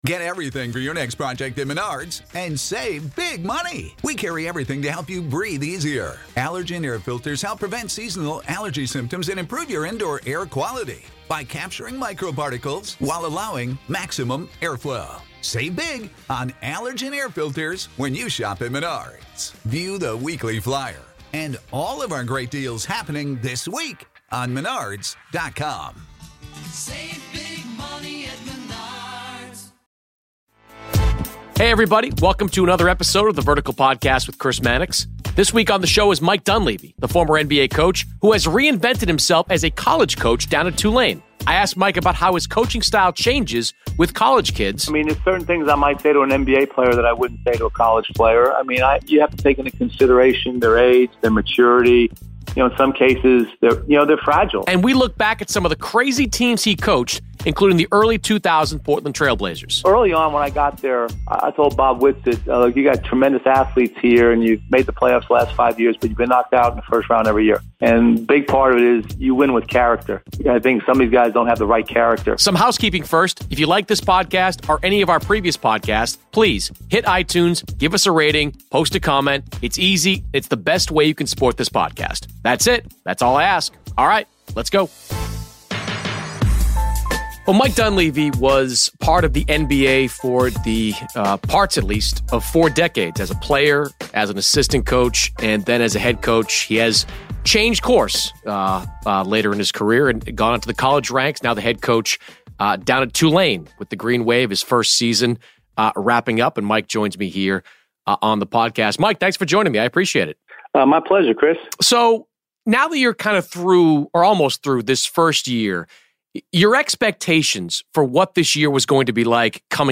Joining Chris Mannix on The Vertical this week is the former NBA Head Coach and current coach at Tulane, Mike Dunleavy Sr. Mike talks with Chris about his thought process of transitioning from the NBA to college.